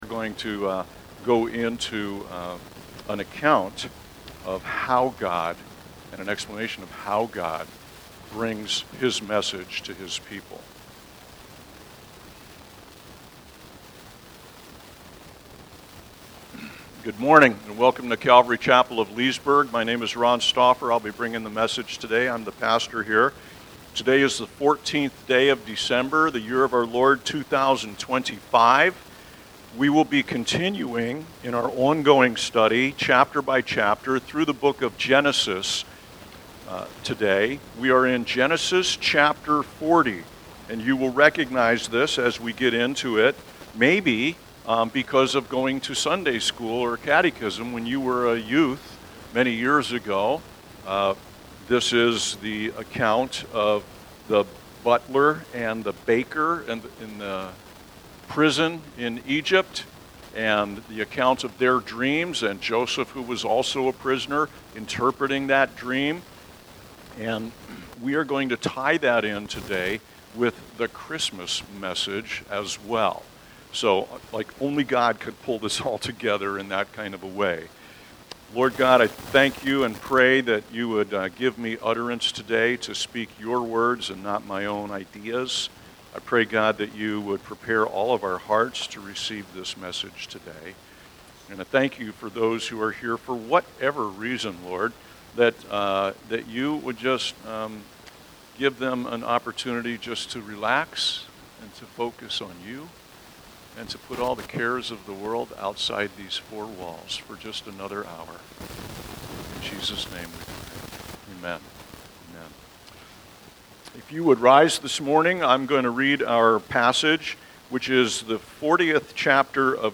Weekly sermons from the Calvary Chapel of Leesburg, Florida.